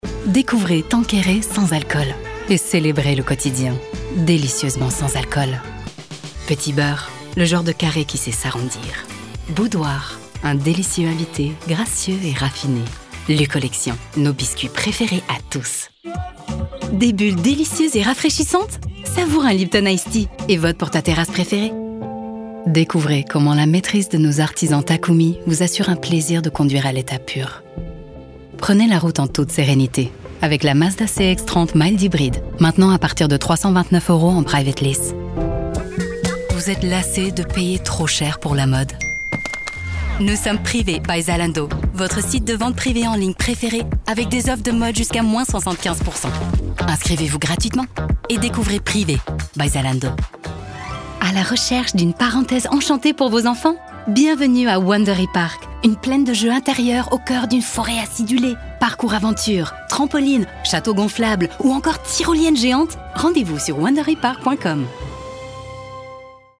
Commerciale, Fiable, Chaude
Commercial
- A voice over artist with an eloquent, neutral French.
- Extremely clear diction with perfect enunciation.
- My voice has been described as smooth, warm, friendly and captivating.